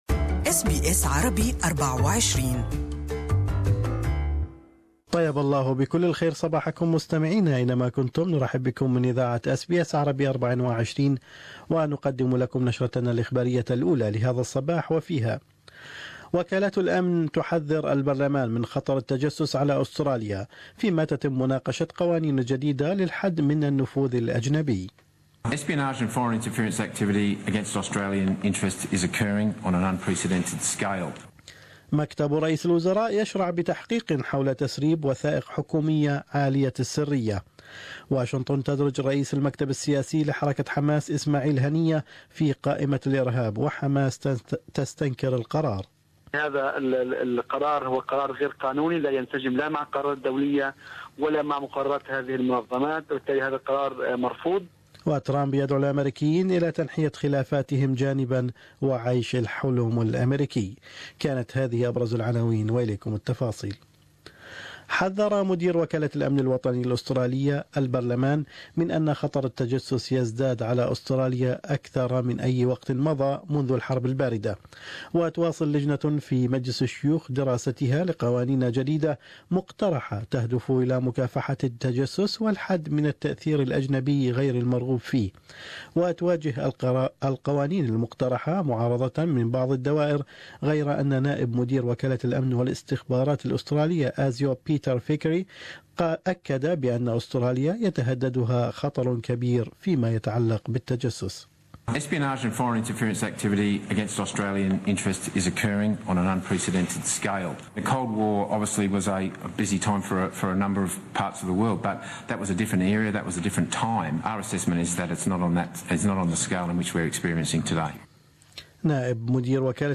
News Bulletin: Australia's chief national security agency warns of a worsening espionage threat